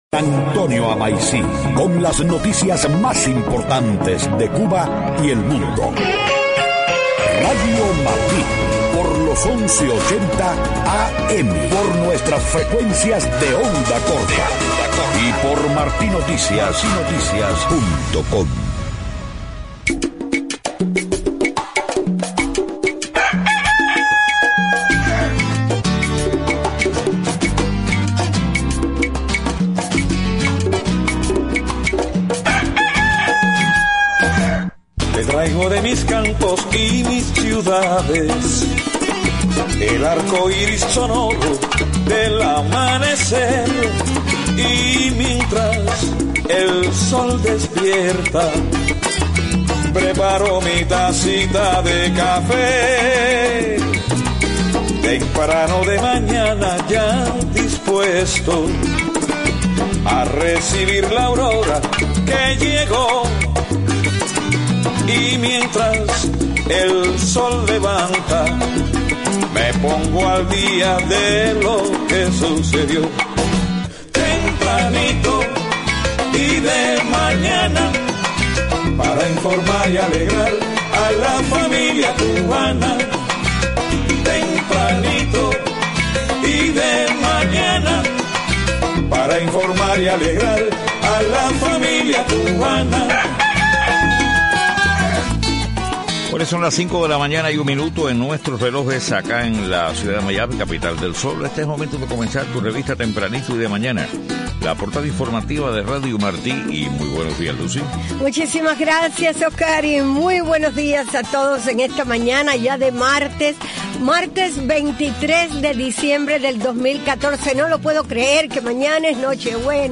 5:00 a.m. Noticias: Gobierno de Cuba dice aceptaría cierta flexibilidad para ofrecer acceso de Internet o remesas para pequeños negocios. Líder republicano Mitch McConnell expresa rechazo a medidas del presidente Obama para normalizar las relaciones con Cuba.